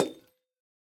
Minecraft Version Minecraft Version snapshot Latest Release | Latest Snapshot snapshot / assets / minecraft / sounds / block / copper_bulb / step2.ogg Compare With Compare With Latest Release | Latest Snapshot